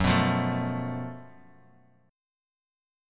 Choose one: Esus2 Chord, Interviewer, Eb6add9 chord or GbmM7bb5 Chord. GbmM7bb5 Chord